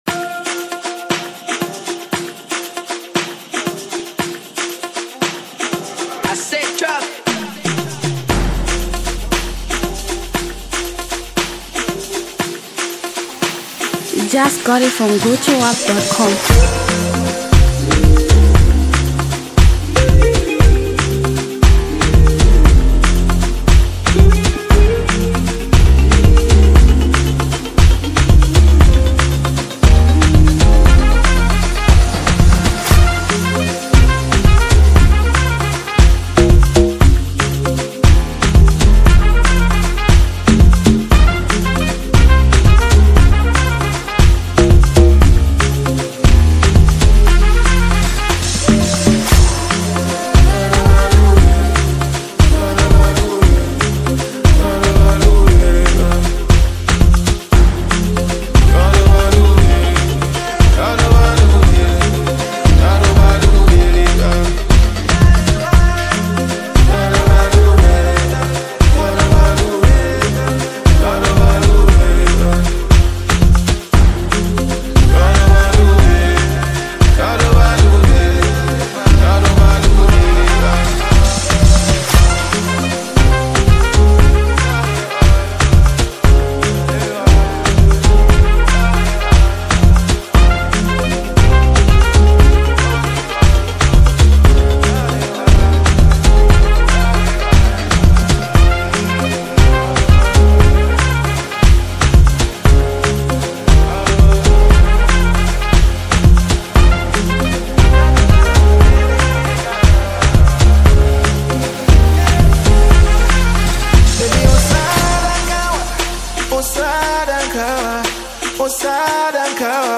is amapiano lyrical